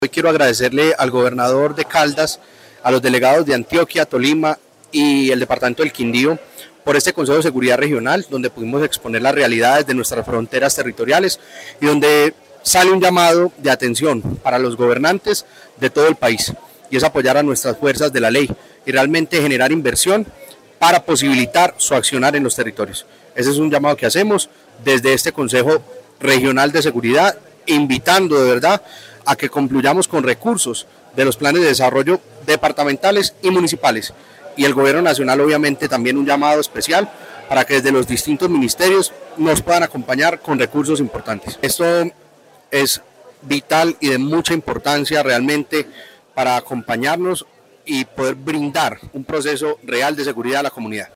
Juan Diego Patiño, Gobernador de Risaralda.